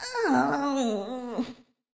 toadette_dead.ogg